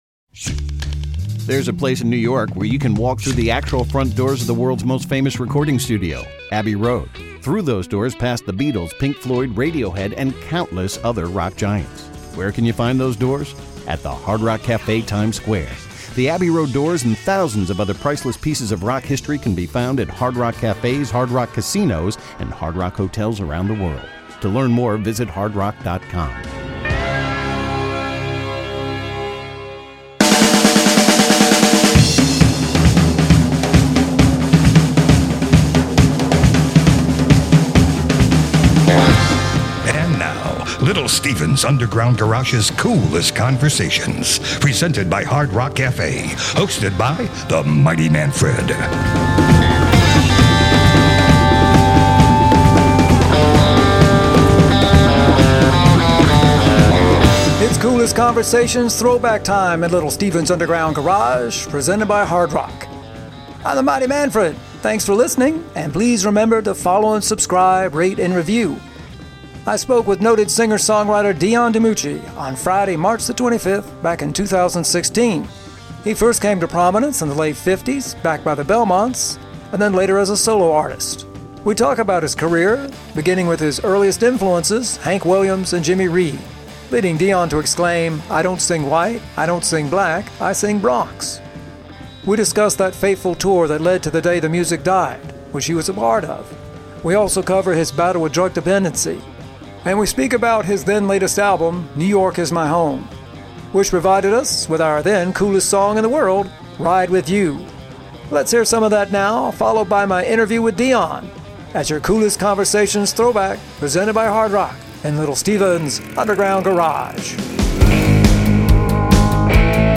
Coolest Conversation